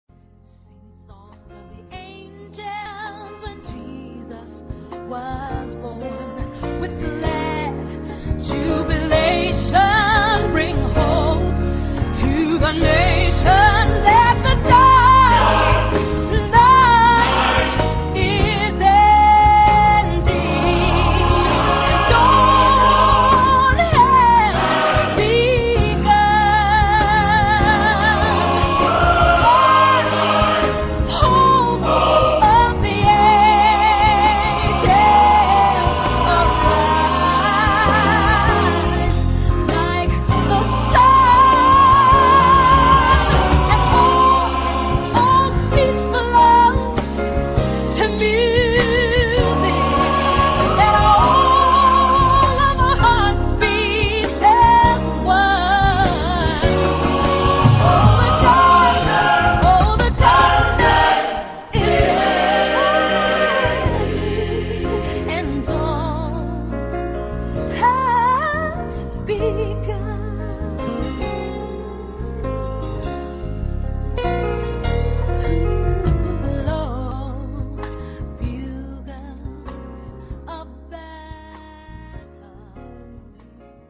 Genre-Stil-Form: Gospel ; geistlich
Chorgattung: SATB  (4 gemischter Chor Stimmen )
Solisten: Mezzo-soprano (1)  (1 Solist(en))
Instrumente: Klavier (1) ; Schlagzeug (1) ; Baß (1)
Tonart(en): As-Dur